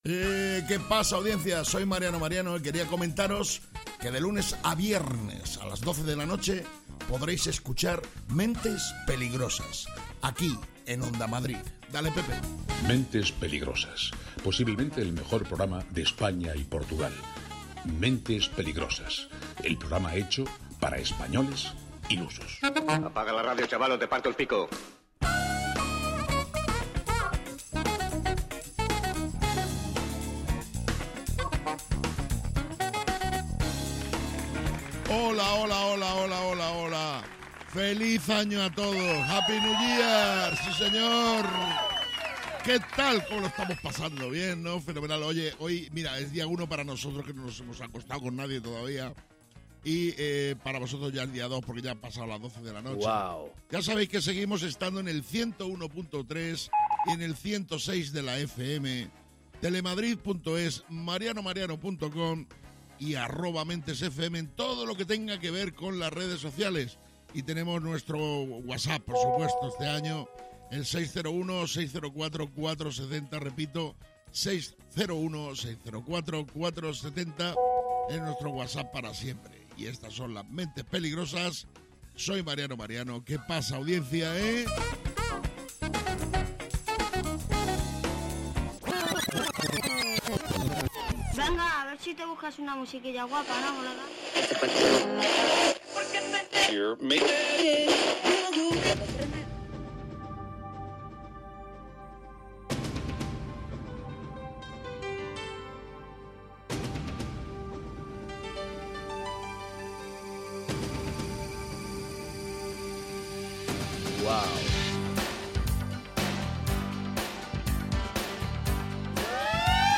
Mentes Peligrosas es humor, y quizás os preguntaréis, ¿y de qué tipo de humor es?